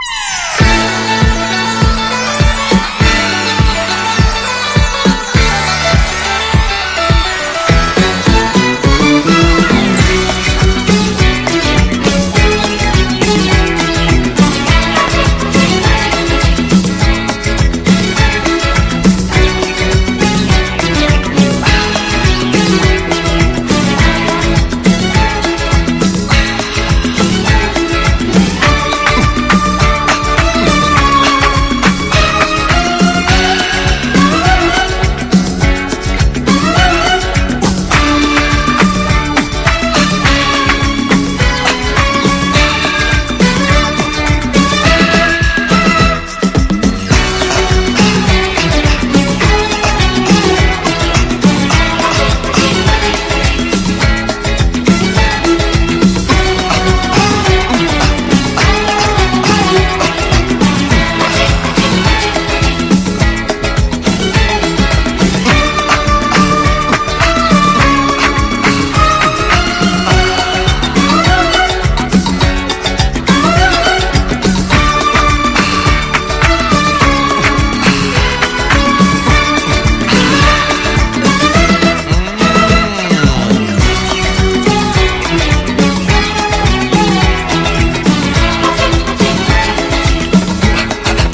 カラフルなアナログ・シンセ・リフに、打ったパーカッション＋艶やかなストリングスで盛り上げるポップなアフロ・ディスコ！